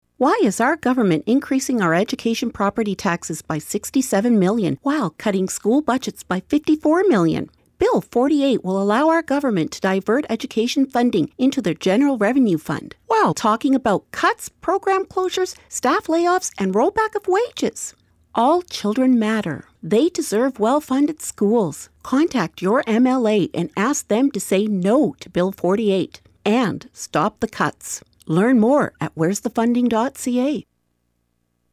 CUPE Education Workers’ Steering Committee launches new radio ad campaign